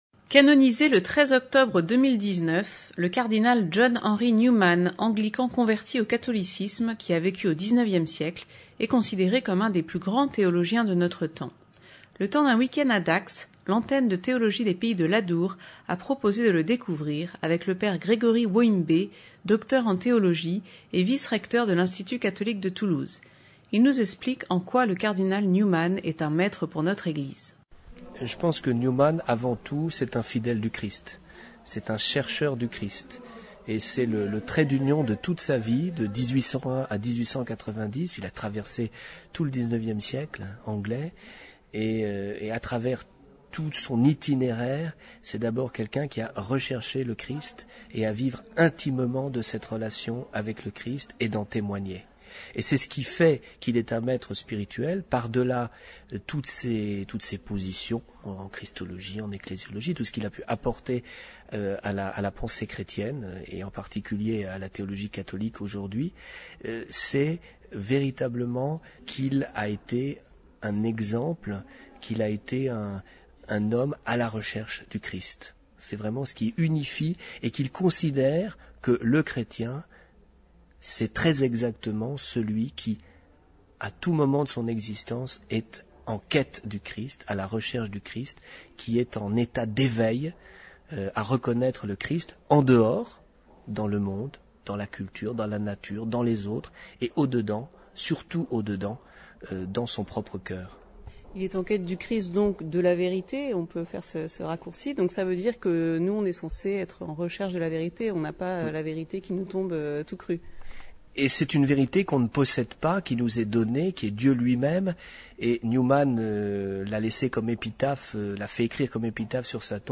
Accueil \ Emissions \ Infos \ Interviews et reportages \ Retour sur la session de l’ATPA consacrée au Saint Cardinal John Henry Newman (...)